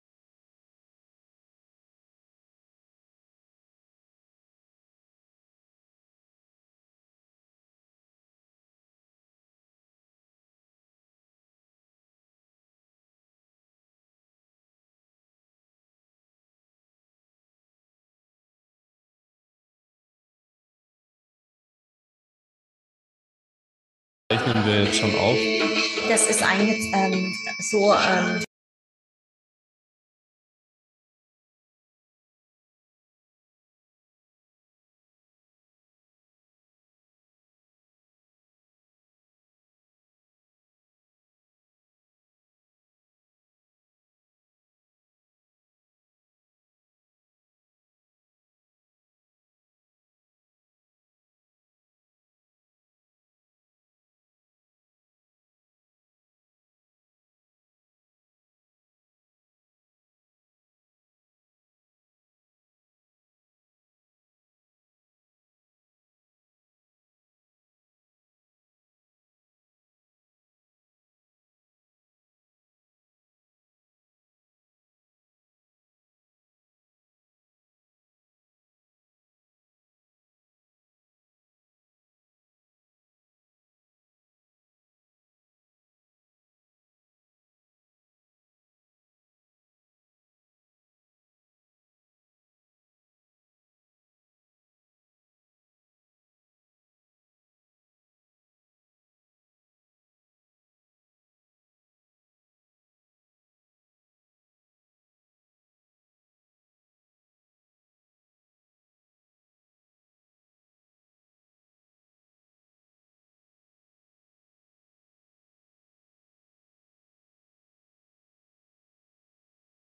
Worte und Frequenzen aus dem göttlichen Feld der Wunder gechannelt für dich und dein MenschSein. Lege dich hinein und lade auf.